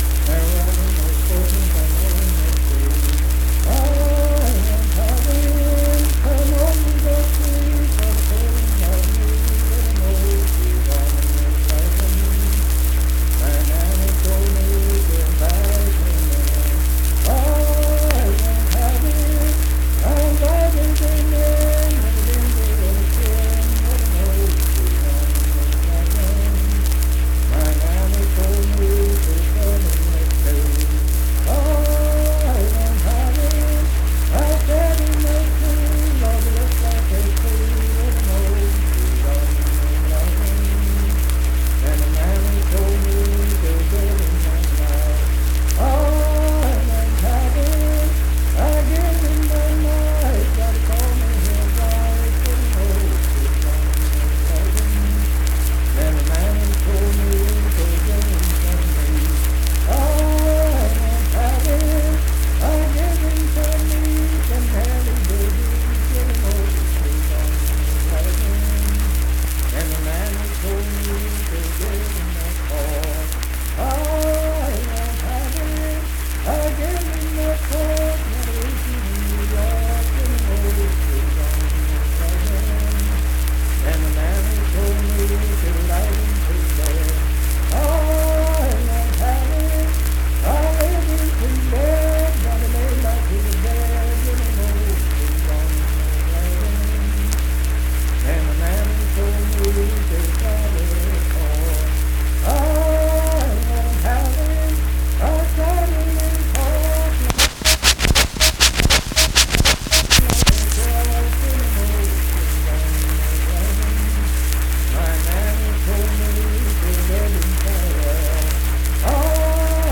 Unaccompanied vocal music
Verse-refrain 7(4w/R). Performed in Kanawha Head, Upshur County, WV.
Voice (sung)